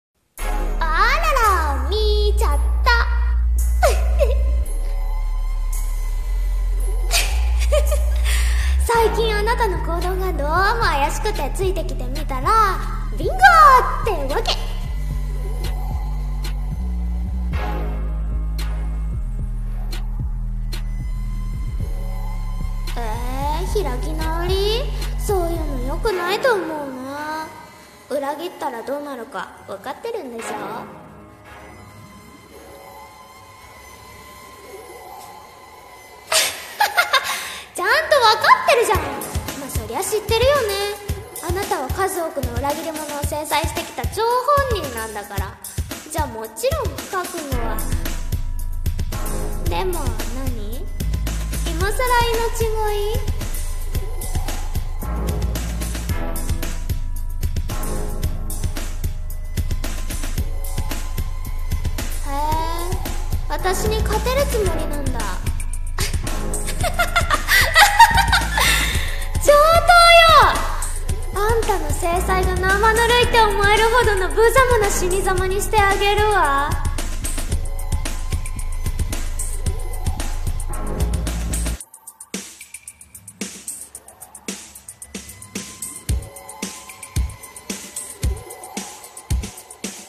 【声劇】裏切りには制裁を【掛け合い】